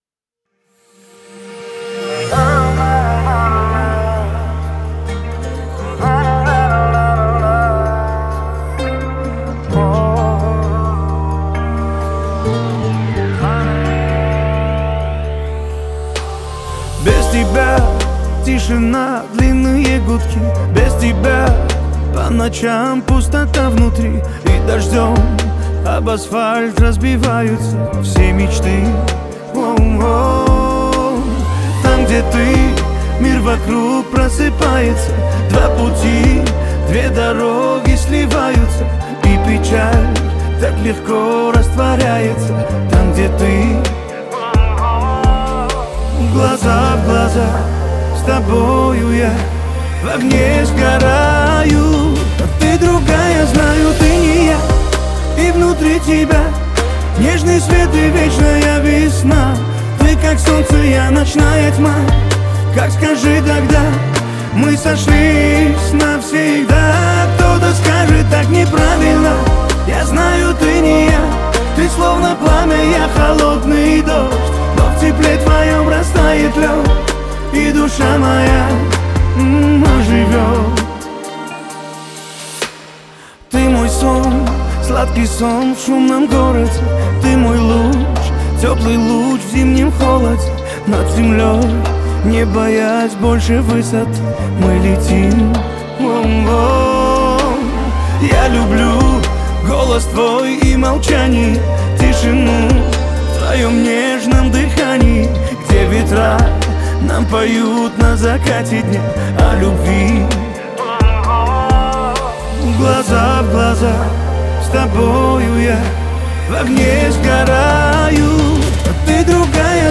Скачать музыку / Музон / Кавказская музыка 2024